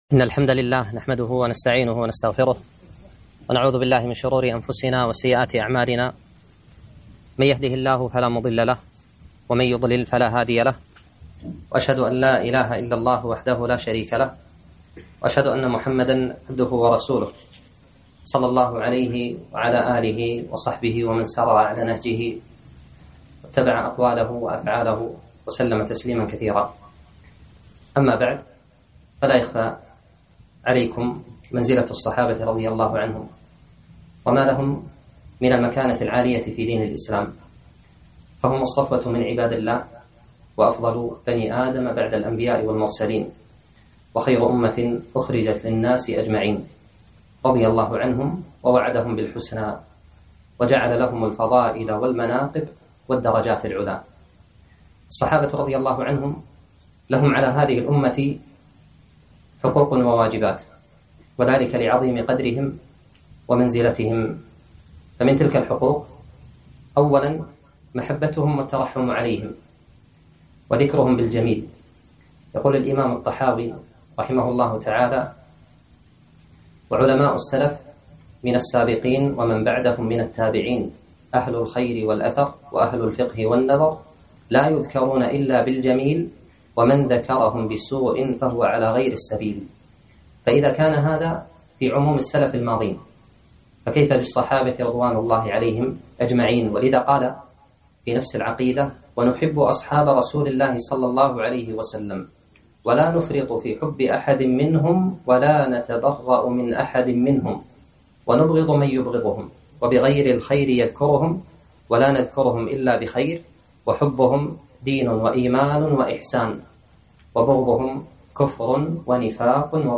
محاضرة - السابقون الأولون